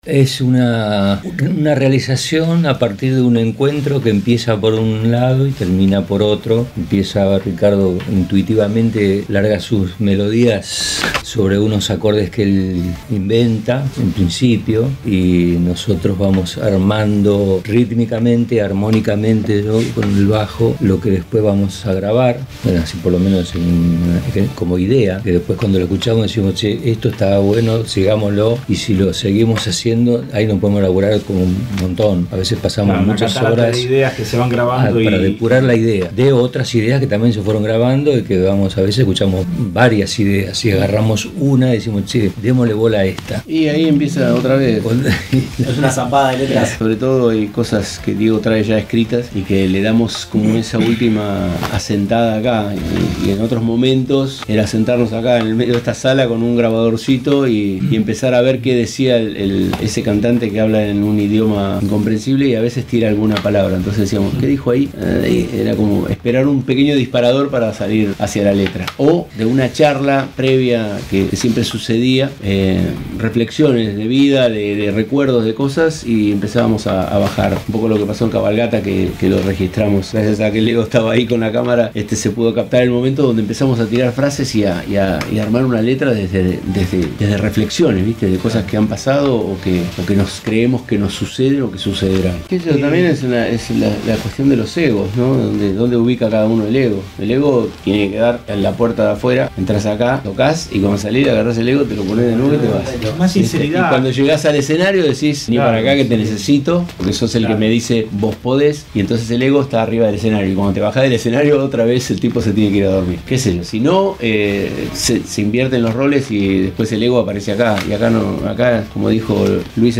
Con motivo de esta visita, Ricardo Mollo, líder de Divididos, fue parte del Mariskal Romero Show – Musicolandia, el show radiofónico español, donde explicó, entre muchas otras cosas, que la intensa actividad sobre los escenarios limitaron su tiempo para componer un disco que ahora ya lo tienen encaminado.
Ricardo-Mollo-con-el-Mariskal-Romero-2.mp3